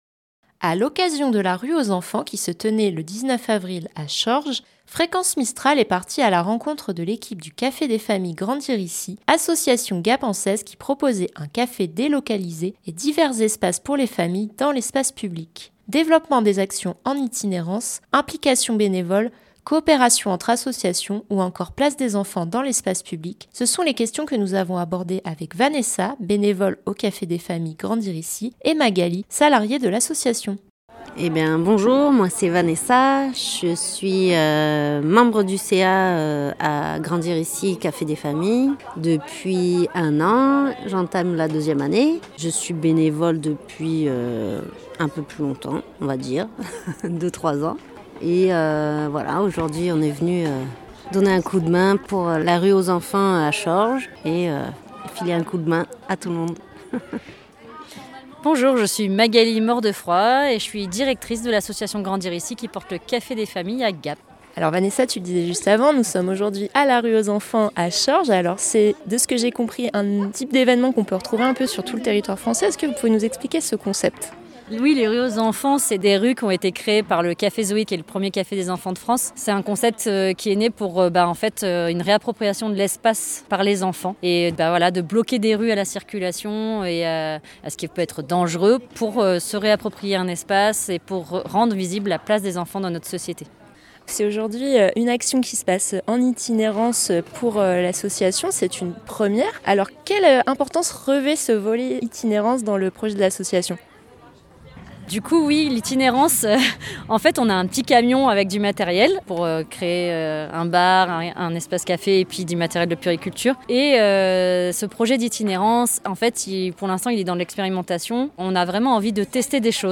À l’occasion de la Rue pour tous, Rue aux enfants, qui se tenait les 18 et 19 avril, à Chorges, Fréquence Mistral est partie à la rencontre de l’équipe du Café des Familles – Grandir Ici, association gapençaise qui proposait un café délocalisé et divers espaces pour les familles, dans l’espace public.